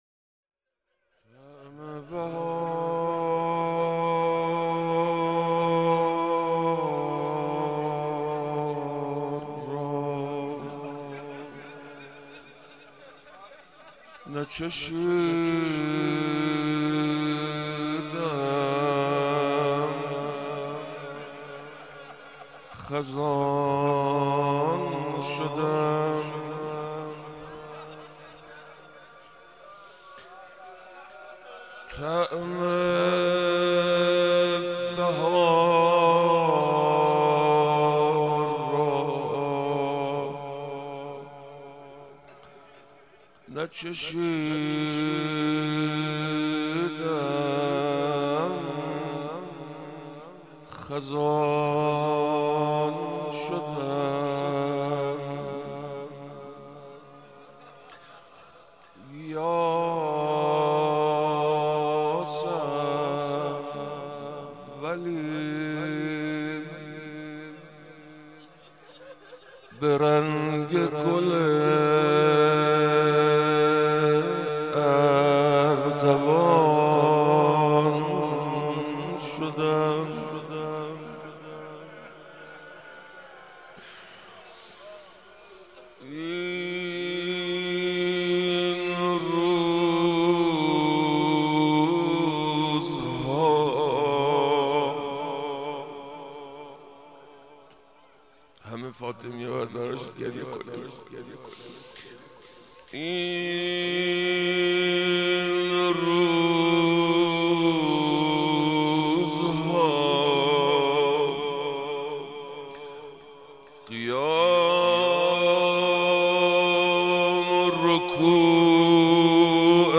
ایام فاطمیه اول - روضه